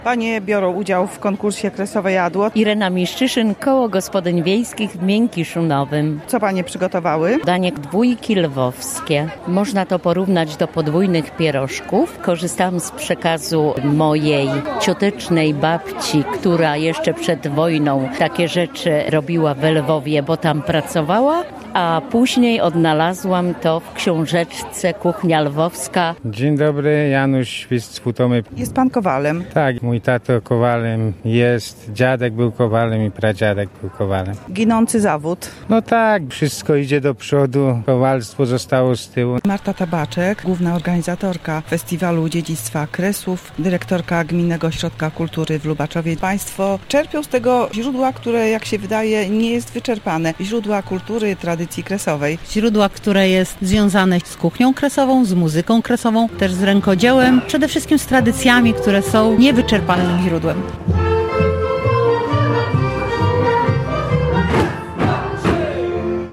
Występy zespołów ludowych, przysmaki kuchni kresowej, kiermasz rękodzieła i pokazy ginących zawodów. W Baszni Dolnej w powiecie lubaczowskim odbył się Wielki Finał XXI-ego Festiwalu Dziedzictwa Kresów.